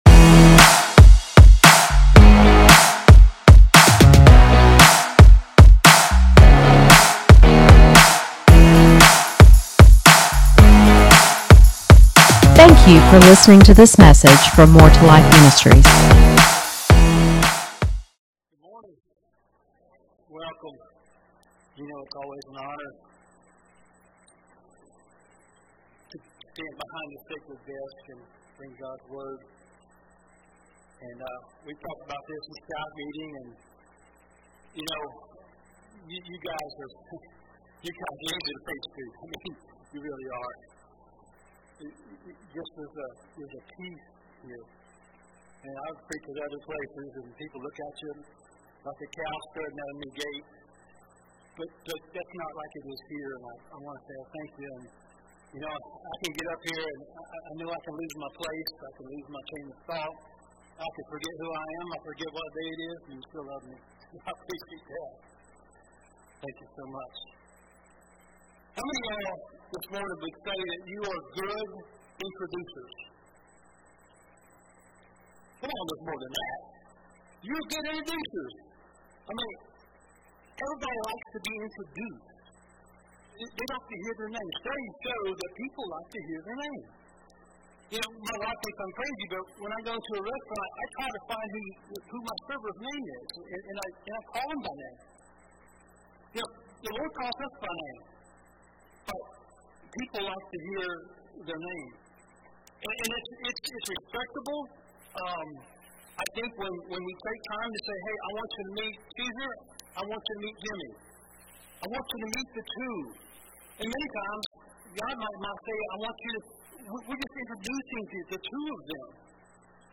Sermons | More 2 Life Ministries